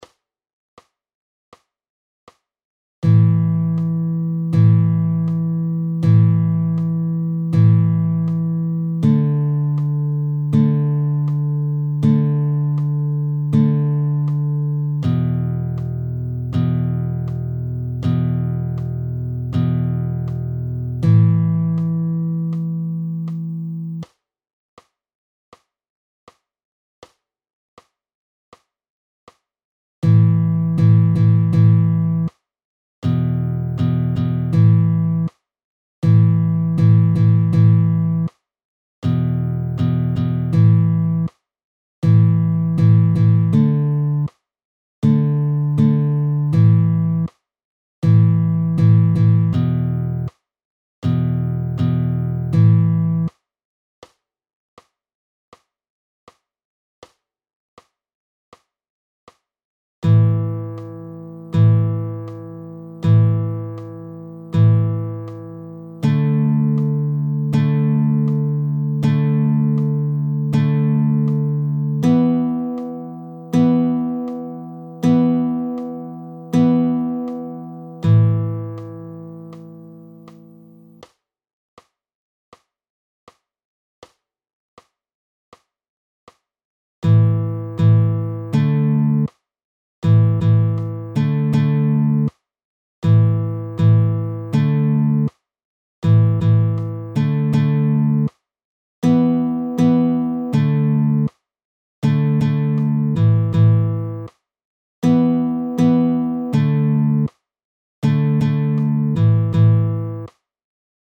1.) Powerchords 2stimmig
I.) Powerchords A5, D5, E5 – 2stimmig: PDF
+ Audio (80 bpm):